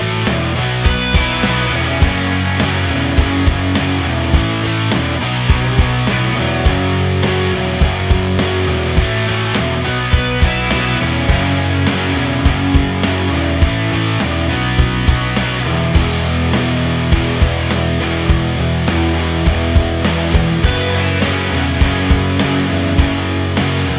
no Backing Vocals Indie / Alternative 4:28 Buy £1.50